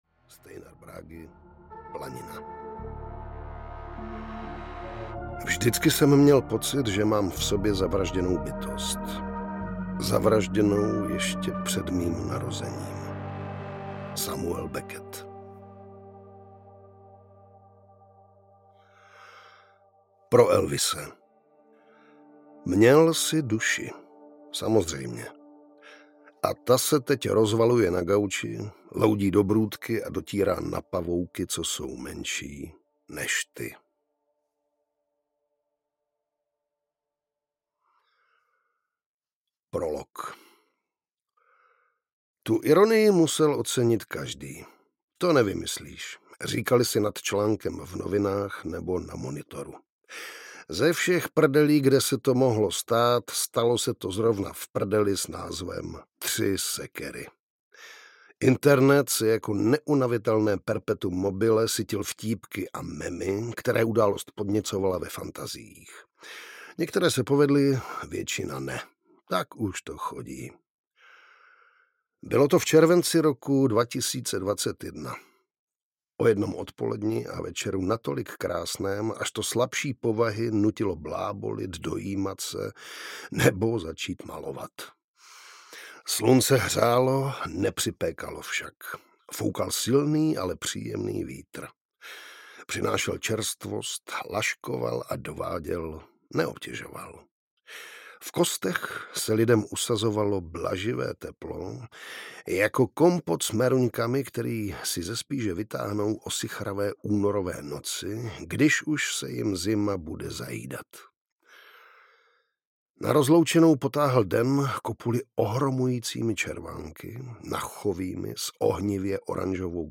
Tři Sekery audiokniha
Ukázka z knihy
• InterpretJiří Vyorálek